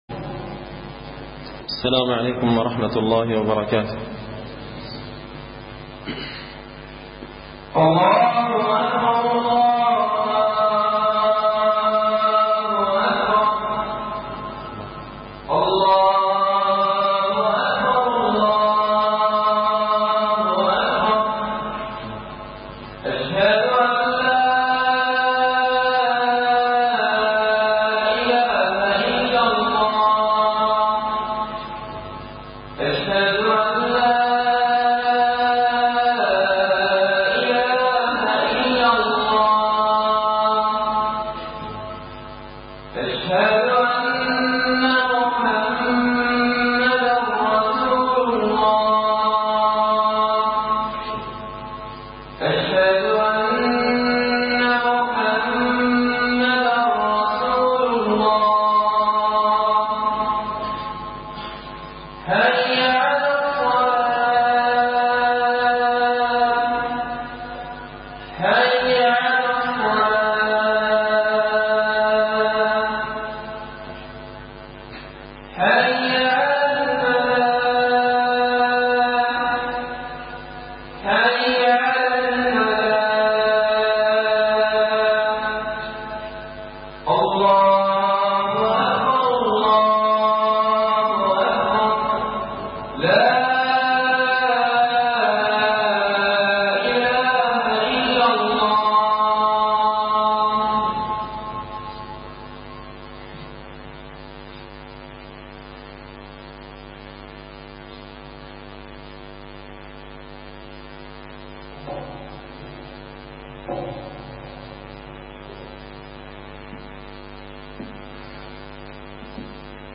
خطبة جمعة بعنوان – الزحزحة من عذاب الله
دار الحديث بمسجد الفرقان ـ قشن ـ المهرة ـ اليمن